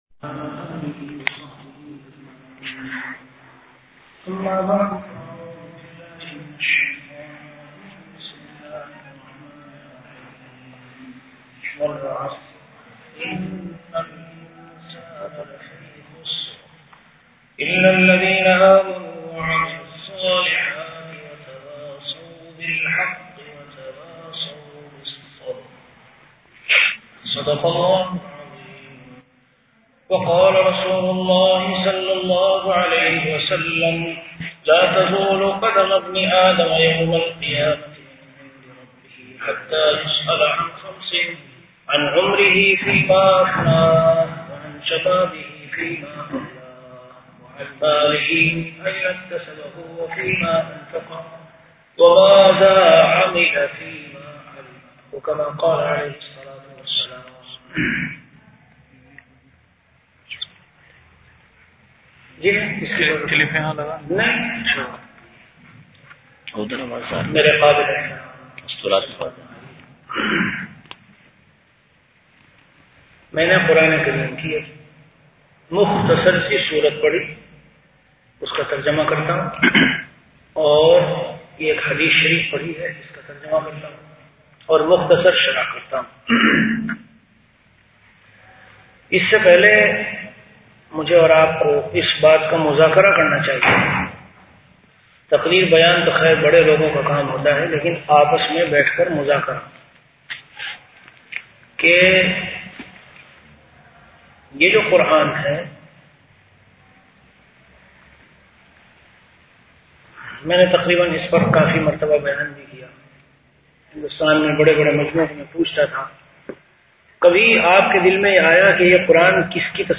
Delivered at Qatar.
Category Bayanat
Event / Time After Fajr Prayer